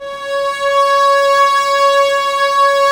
Index of /90_sSampleCDs/Roland L-CD702/VOL-1/STR_Vlas Bow FX/STR_Vas Sordino